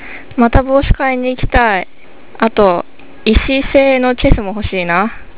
研修生の声　２